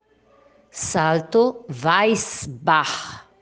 Porém, o modo considerado correto, é falar com som de R ao final da palavra.
Pronúncia: Salto Vais-barr